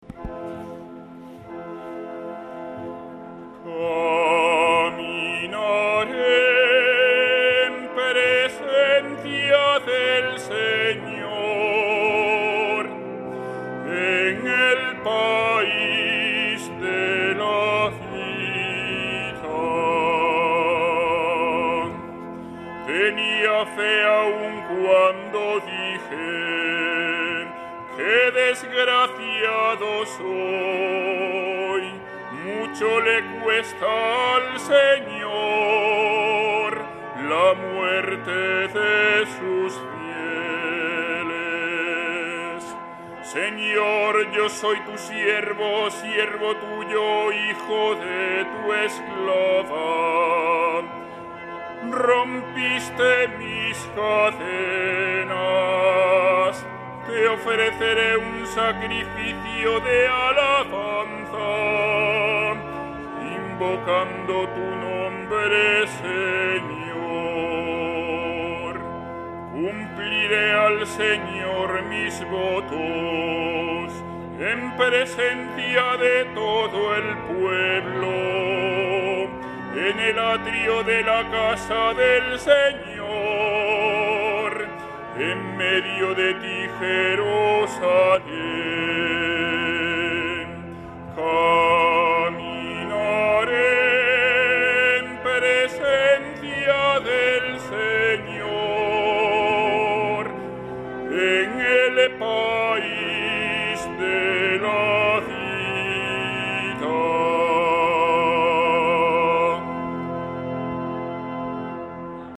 Salmo Responsorial 115/ 10; 15-19